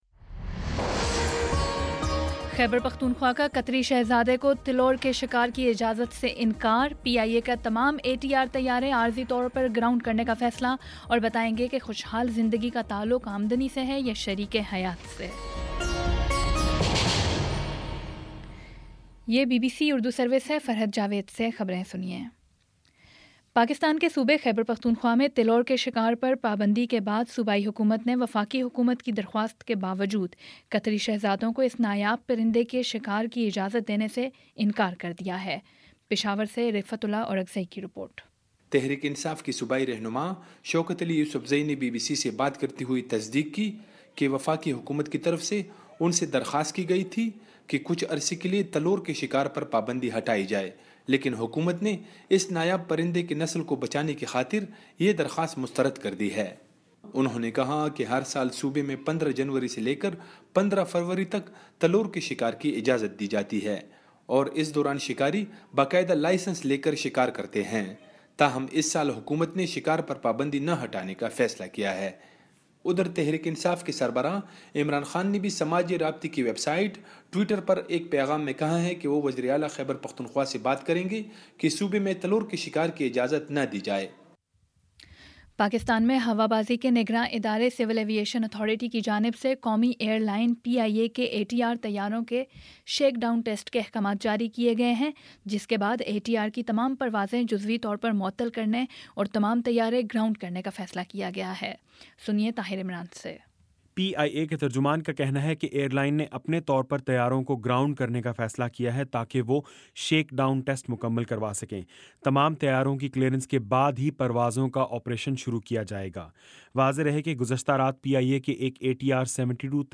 دسمبر 12 : شام سات بجے کا نیوز بُلیٹن